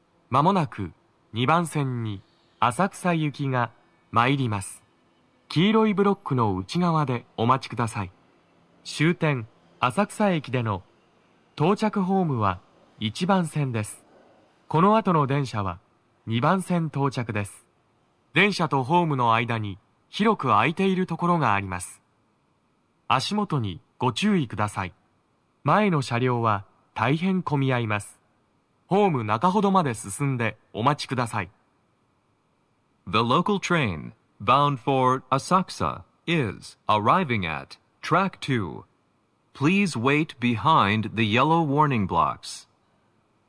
鳴動は、やや遅めです。
2番線 浅草方面 接近放送 【男声
接近放送2